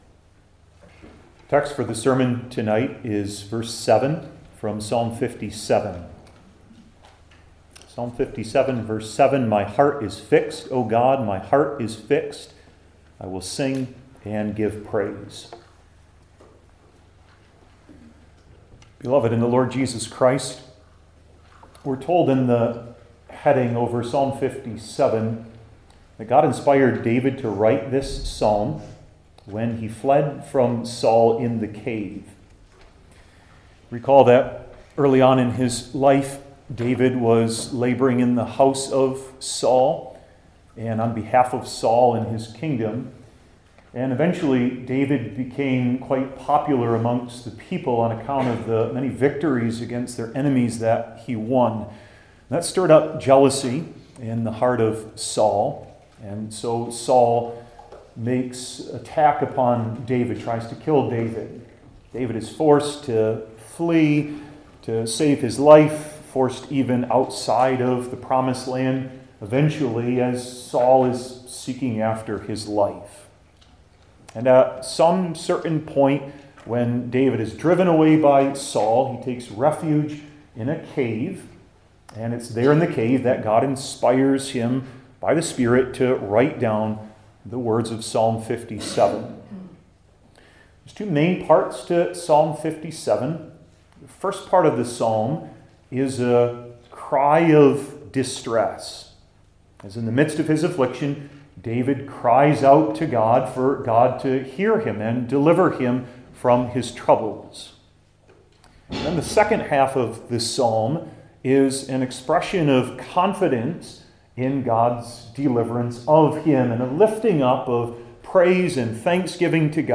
Old Testament Individual Sermons I. The Meaning II.